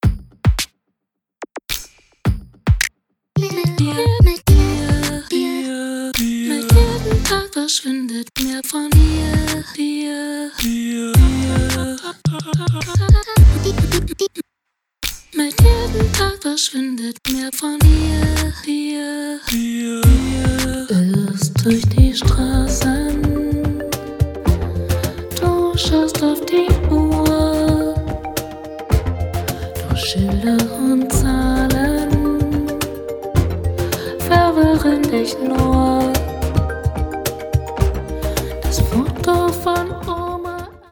Gegen etwas Electro habe ich nichts.